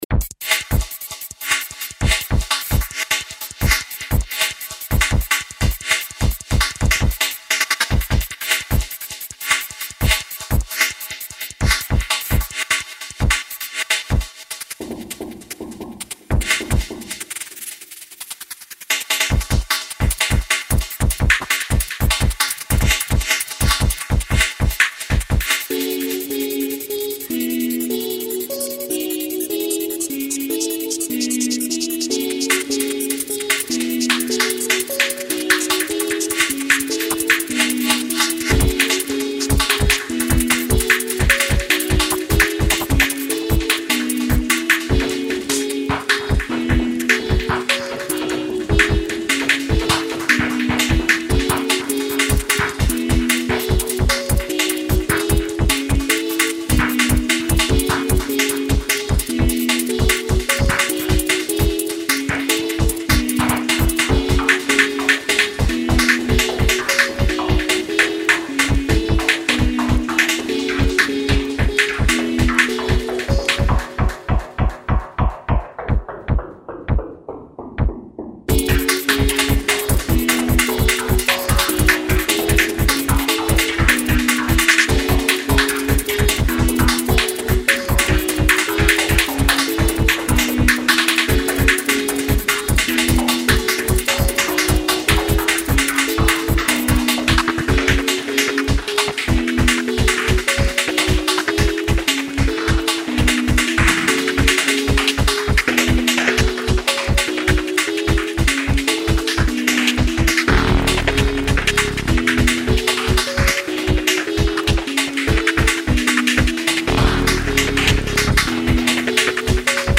morceau ne contenant aucune mesure identique
Electro